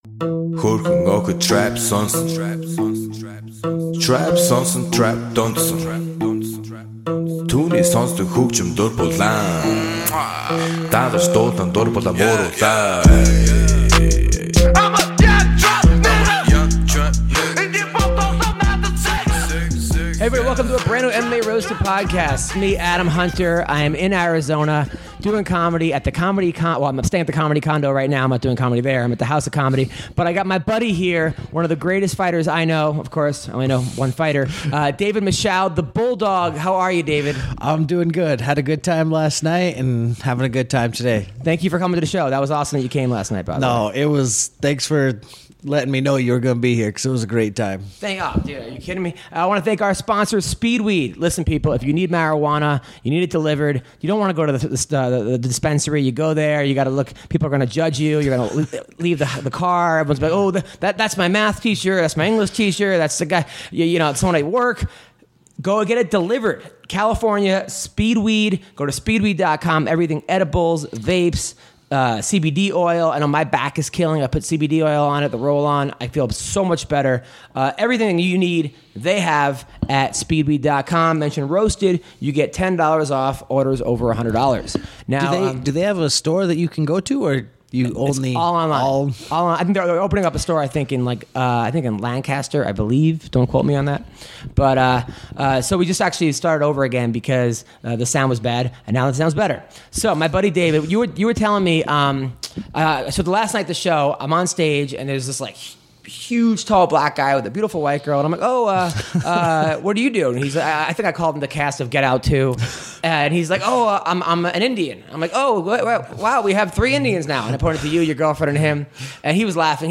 joined in studio